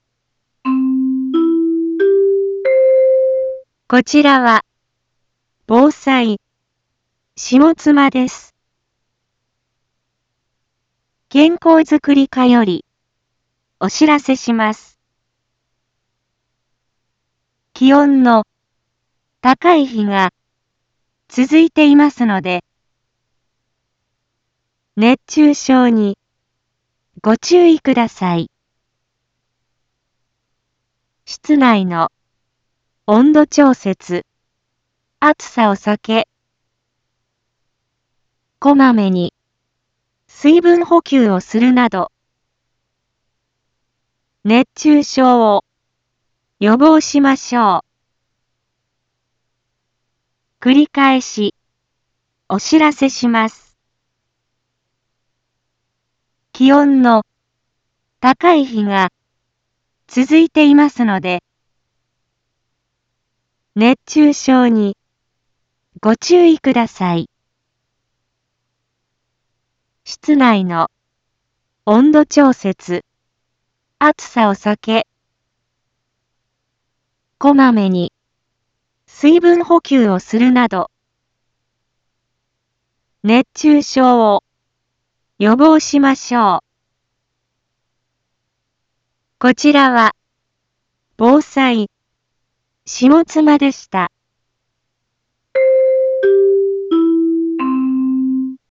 一般放送情報
Back Home 一般放送情報 音声放送 再生 一般放送情報 登録日時：2023-07-18 11:01:42 タイトル：熱中症注意のお知らせ インフォメーション：こちらは、防災、下妻です。